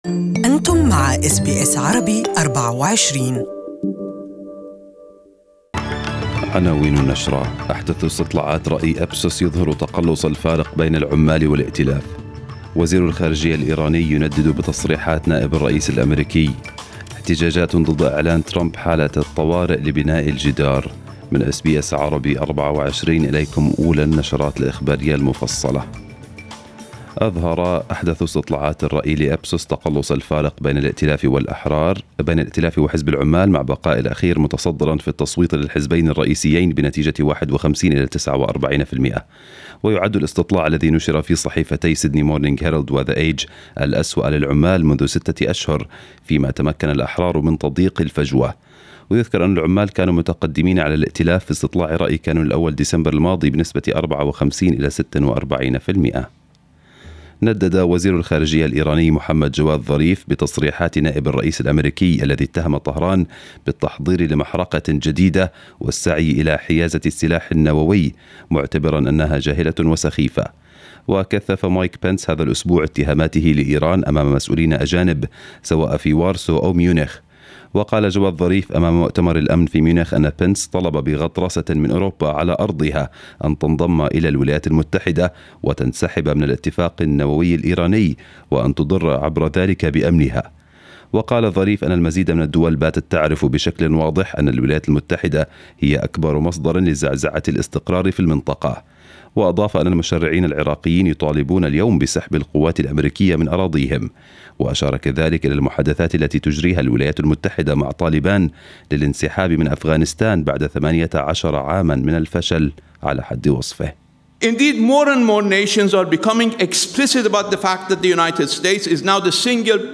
نشرة الاخبار باللغة العربية لهذا الصباح